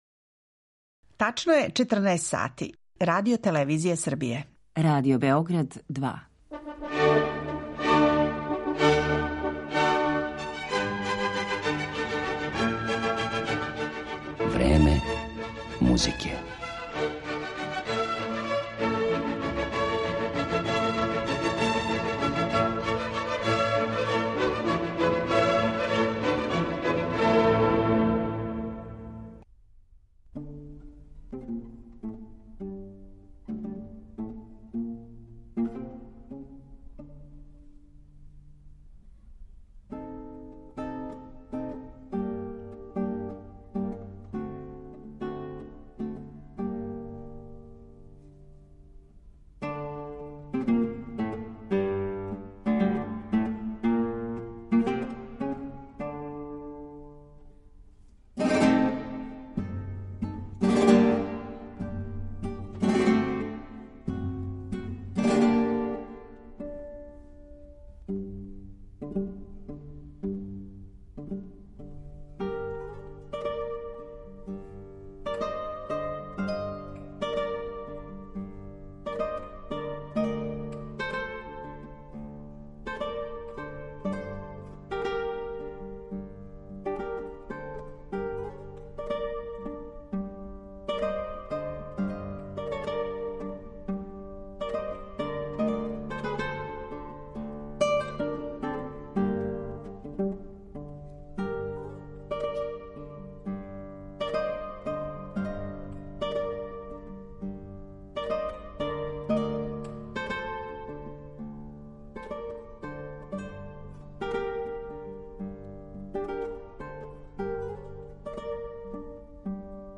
Слушаћете овај свима познати ритам који разуме и воли цело човечанство и то у остварењима композитора разних стилова који су га уврстили у своју свиту, концерт, квартет, оперу, балет, симфонију, па чак и мису!